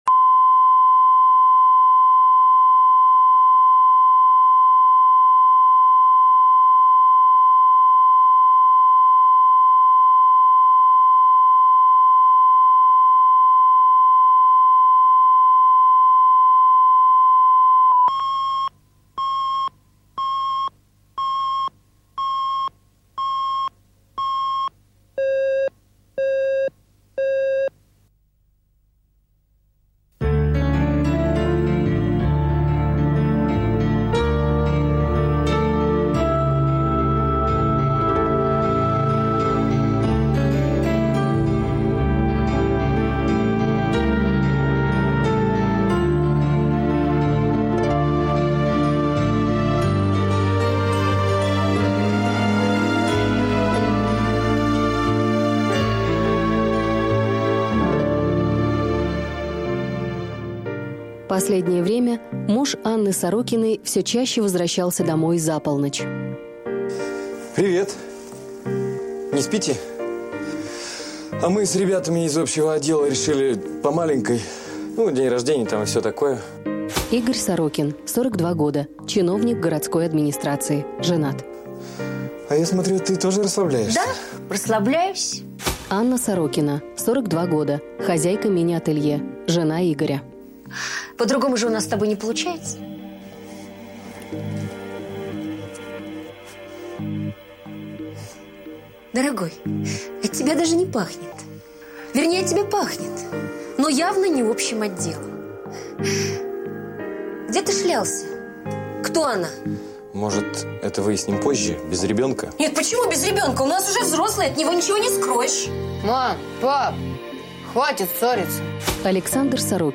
Аудиокнига Плохой папа | Библиотека аудиокниг